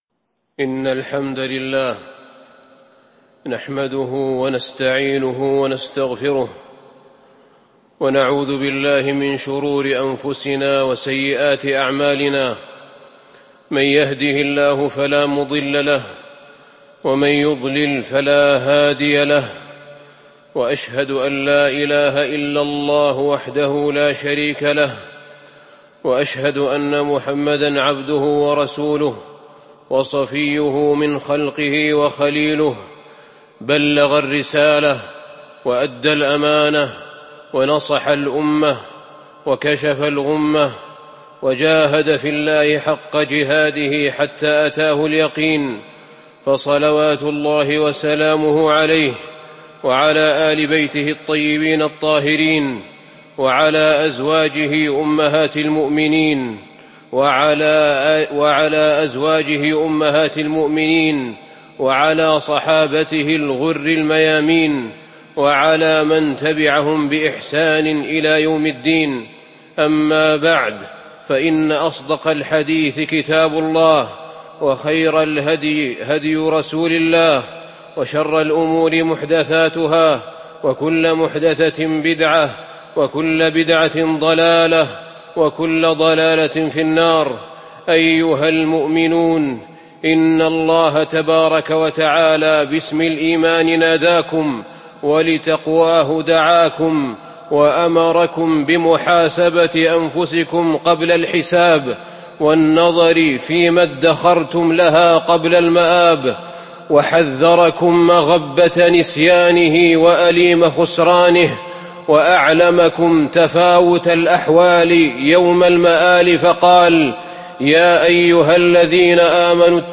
تاريخ النشر ٣ جمادى الأولى ١٤٤٢ هـ المكان: المسجد النبوي الشيخ: فضيلة الشيخ أحمد بن طالب بن حميد فضيلة الشيخ أحمد بن طالب بن حميد هو الله